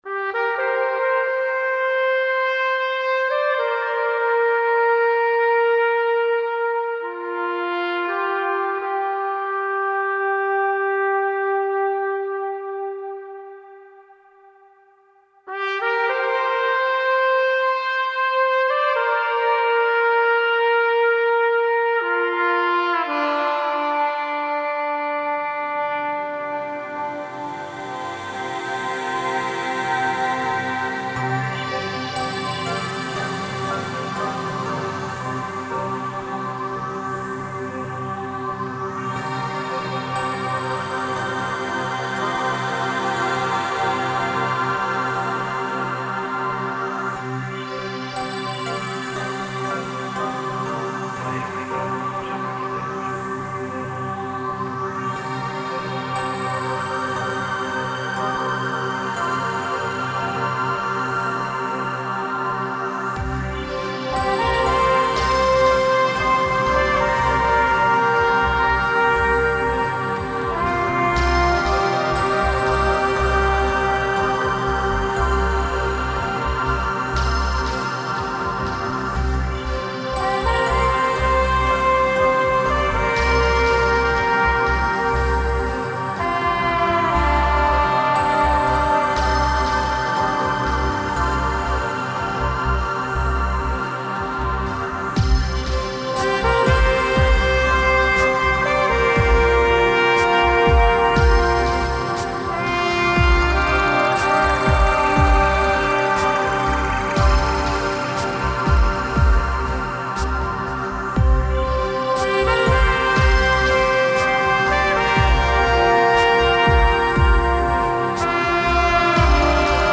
flugelhorn or trumpet